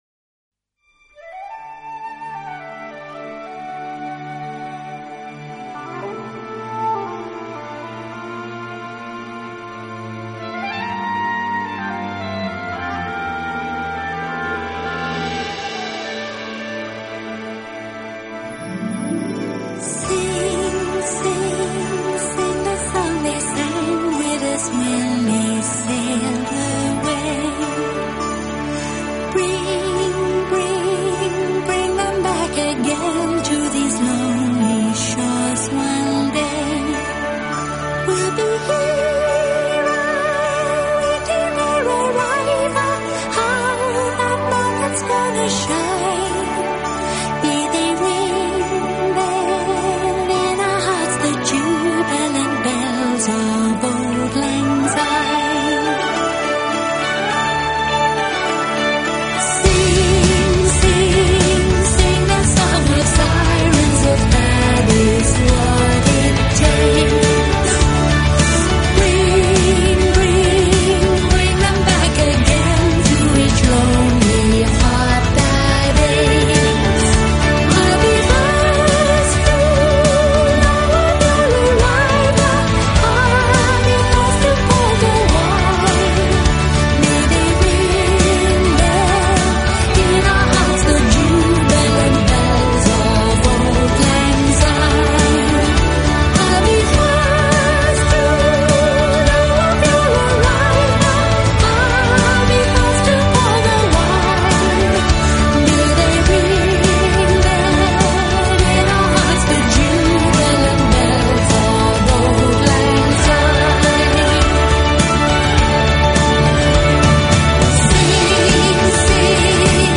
音乐流派：Crossover，Classical，Vocal